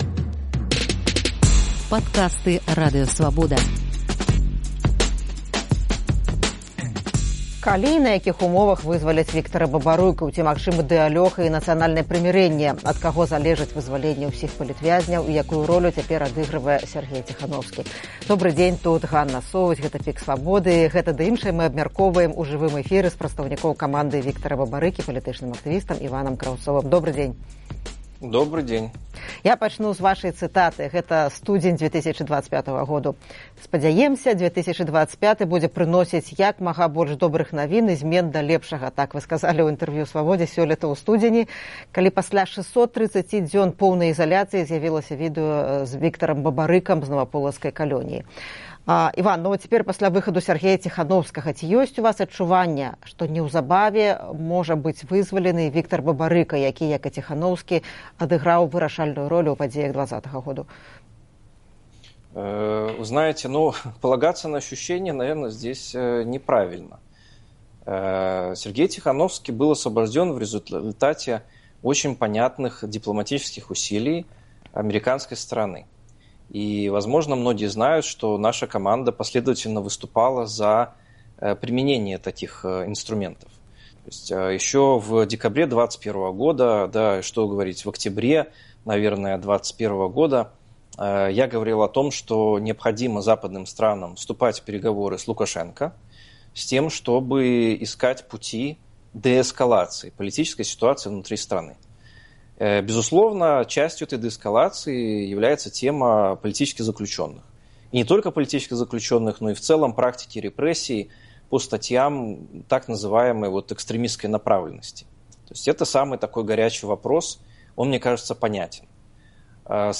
Гэта ды іншае ў жывым эфіры «ПіКу Свабоды»